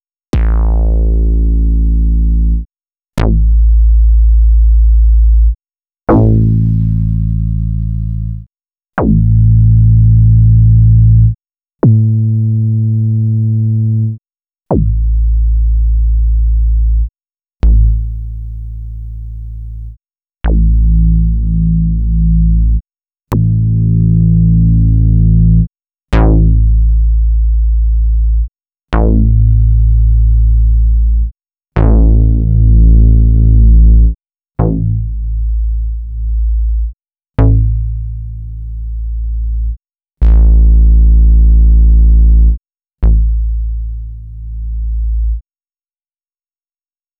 14_FatBass.wav